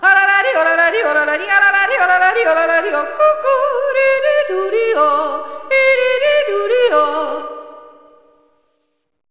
yodel.wav